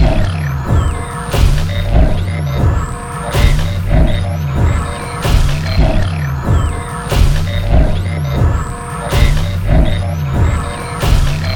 lark.ogg